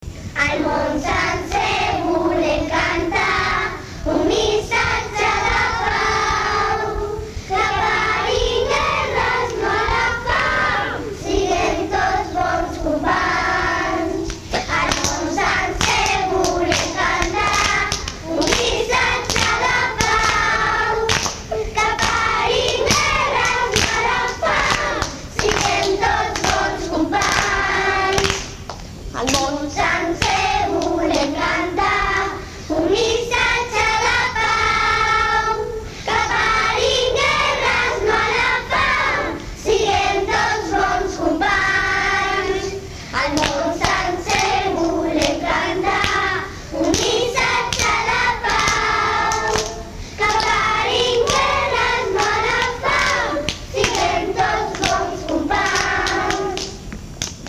Els alumnes canten el
Tots plegats cantem un NO A LA GUERRA.